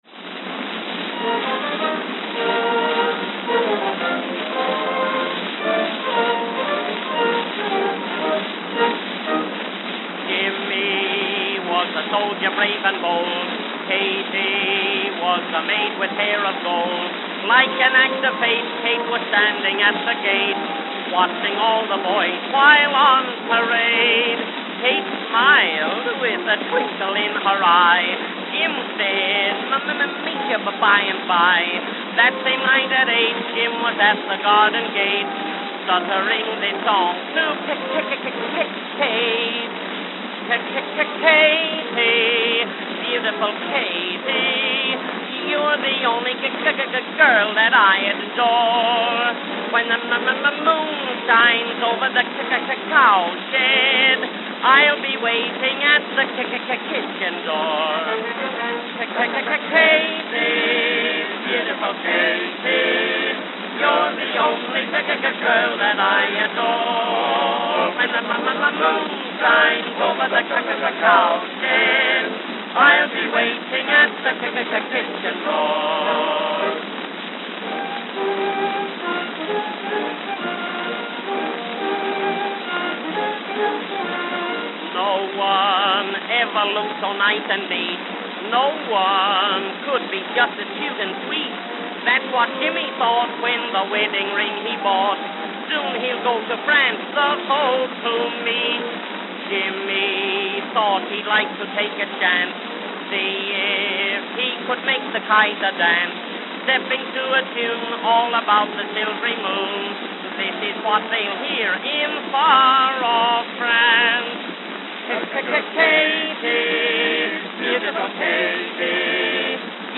Edison Diamond Discs
Note: Very worn.